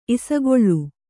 ♪ isagoḷḷu